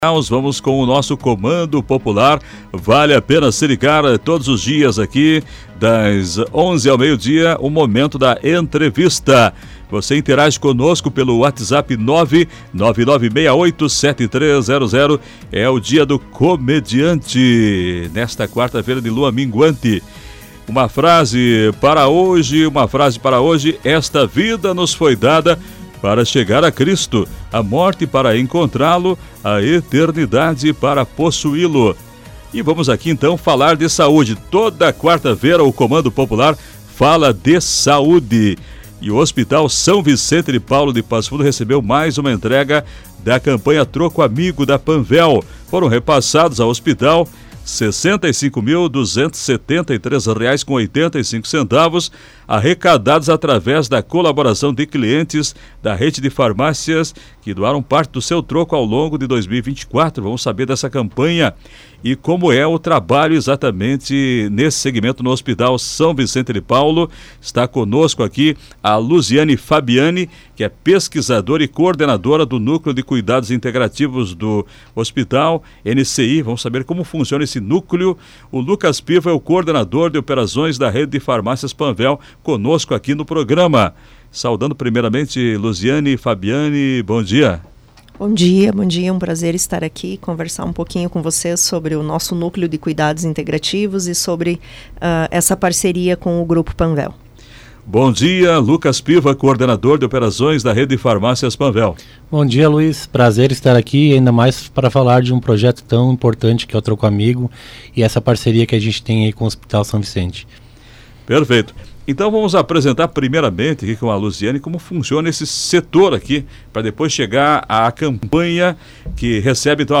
Foto – Comunicação HSVP Esse foi o tema do quadro da saúde no programa Comando Popular, da Rádio Planalto News (92.1), nesta quarta-feira, 26.